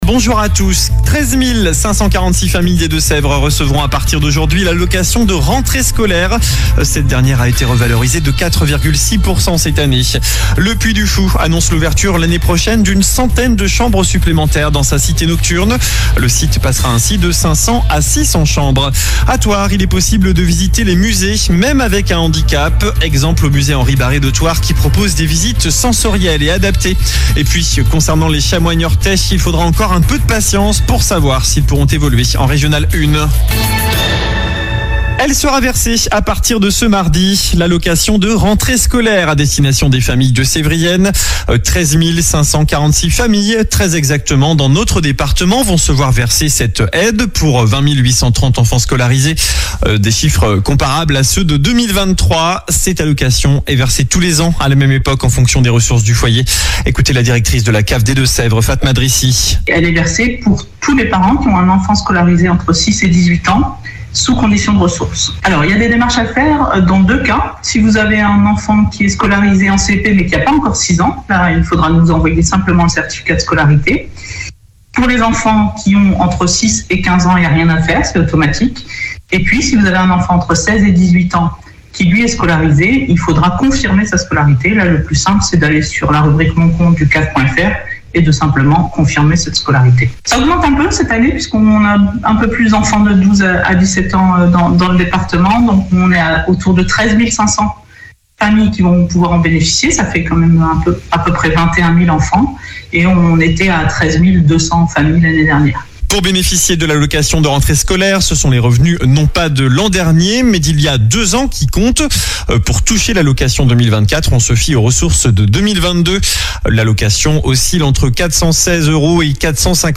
JOURNAL DU MARDI 20 AOÛT
infos locales